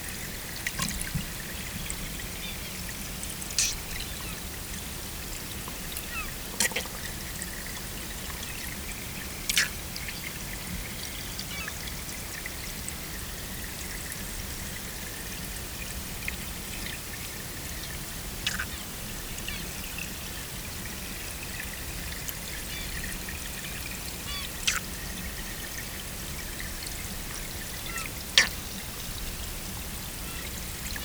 Recording contains multiple sounds